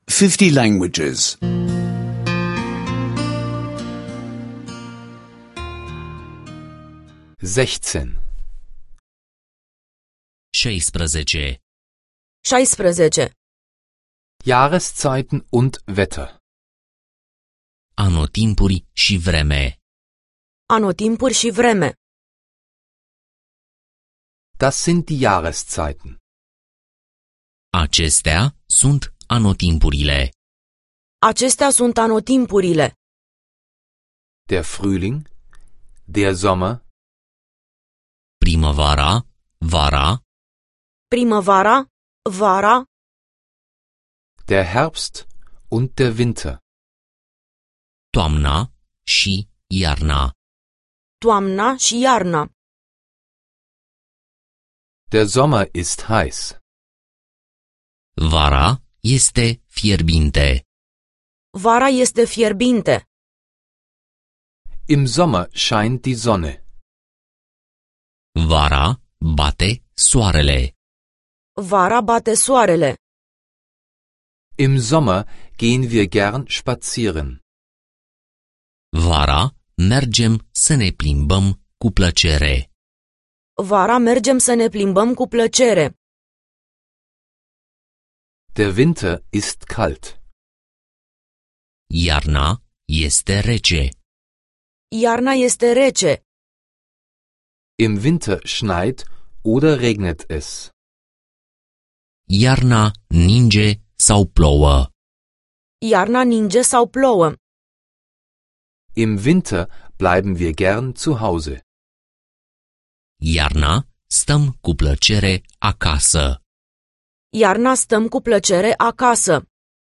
Rumänischen Audio-Lektionen, die Sie kostenlos online anhören können.